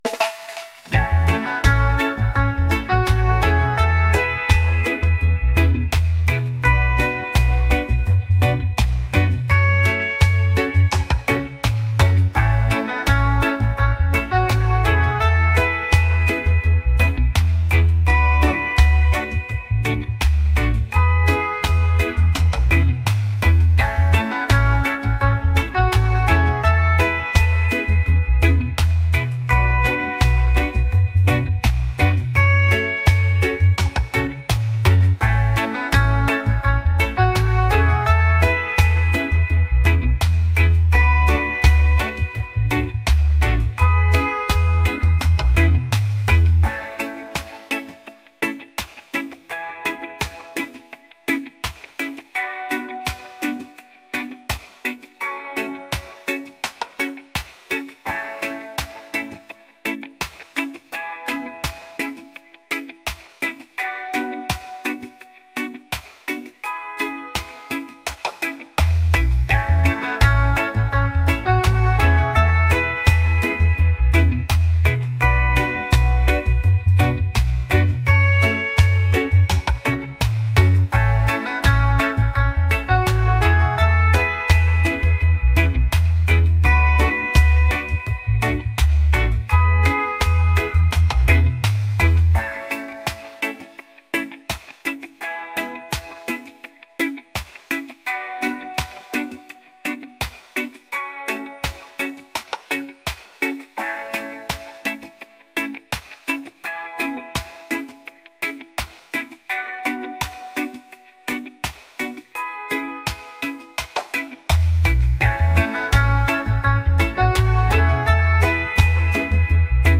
reggae | groovy | mellow